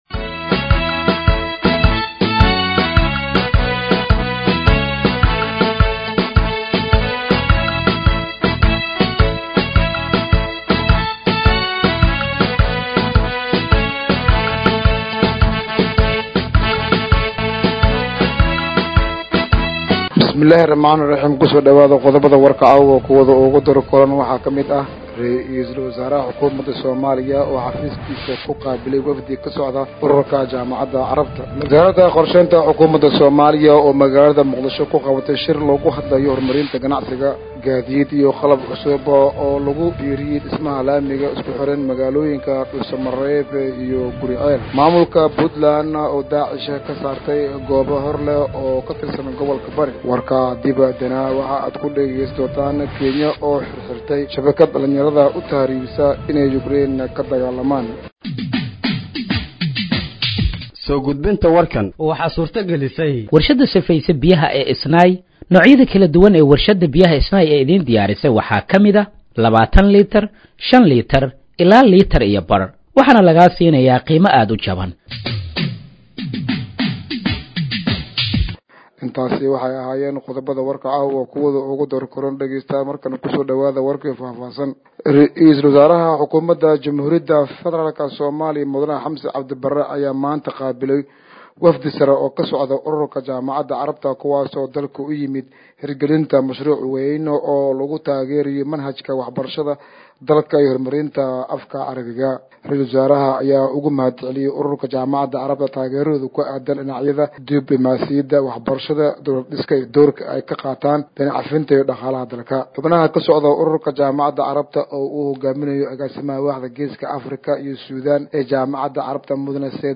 Dhageeyso Warka Habeenimo ee Radiojowhar 27/09/2025